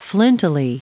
Prononciation du mot flintily en anglais (fichier audio)
Prononciation du mot : flintily